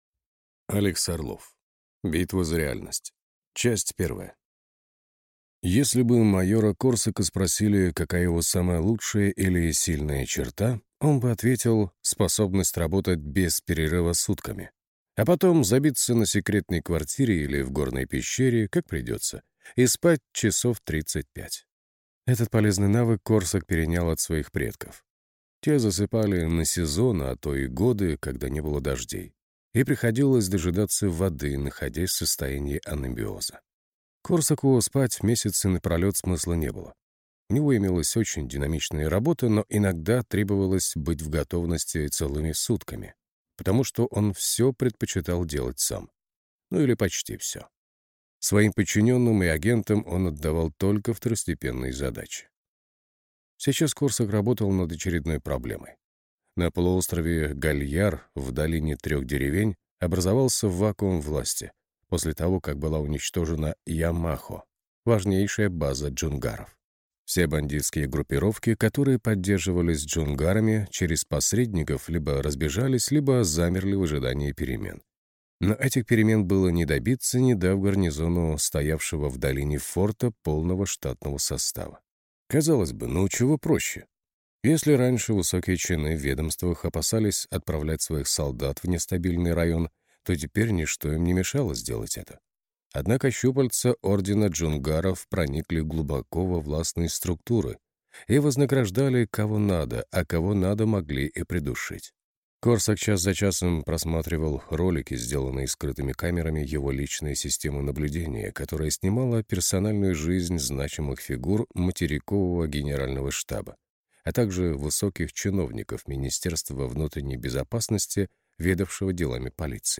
Аудиокнига Битва за реальность | Библиотека аудиокниг
Прослушать и бесплатно скачать фрагмент аудиокниги